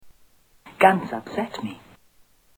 Guns